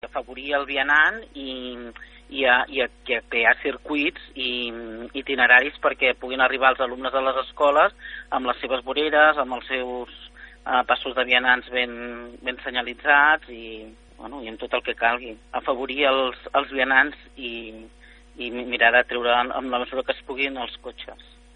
De tot plegat en parla Susanna Pla, regidora de Governació de l’Ajuntament de Palafolls.